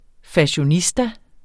Udtale [ faɕoˈnisda ]